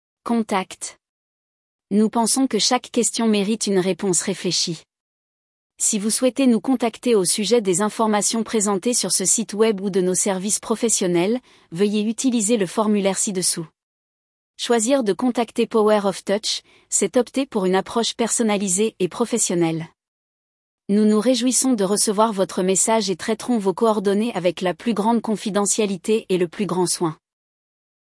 mp3-text-to-voice-contact-fr-power-of-touch.mp3